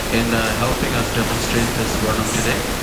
A further problem you'll have is that the audio is so low that its buried down in the ambient noise. So after you increase the volume the background noise will be pretty loud too. But at least you can hear the voice.
It is noisy.